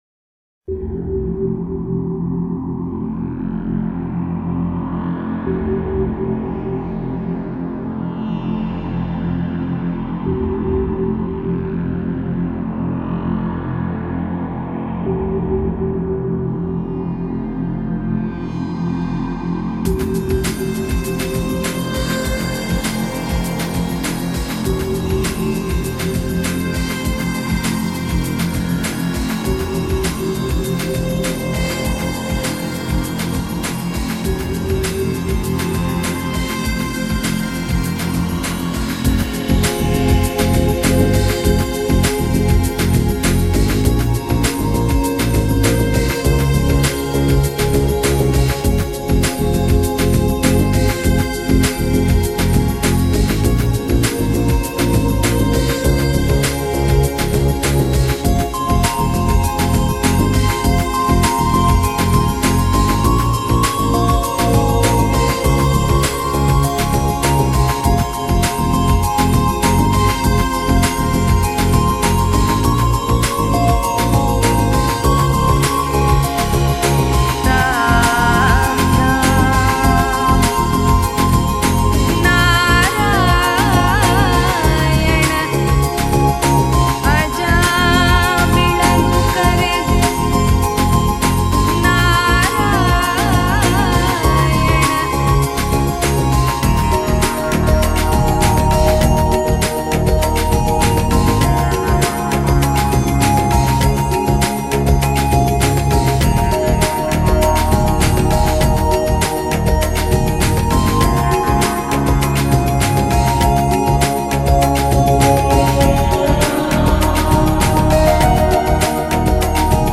基本上其音域范围跨越了从冥想舒缓类到节奏明快充满激情，但有时又有些抑郁色彩的类别。
声音象从某个深处传来，在黑夜里，张开一大蓬的光，罩着你，整个身体随即透明起来。
这是一种炫丽如钻又幻魅若灵的乐唱。